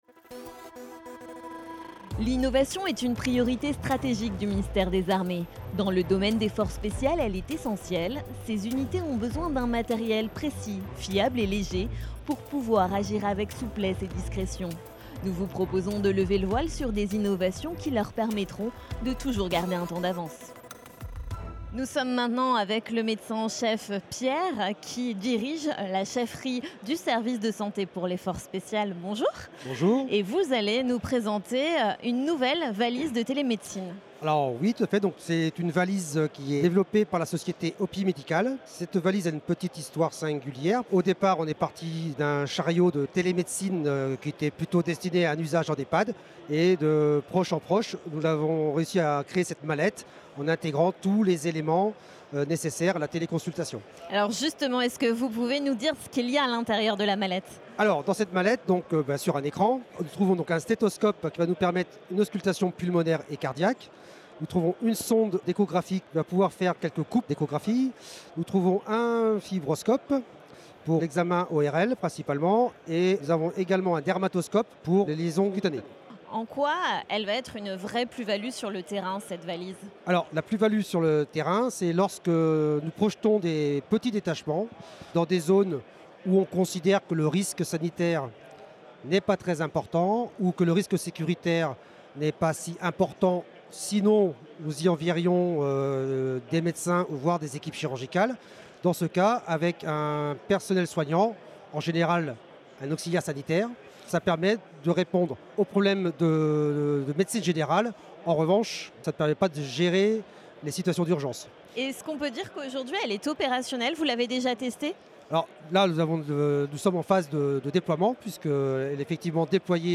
A l’occasion de la Journée mondiale de la créativité et de l’innovation, la rédaction vous propose, pendant deux jours, d’écouter des innovateurs civils et militaires qui ont mobilisé leurs idées et talents au service des forces spéciales.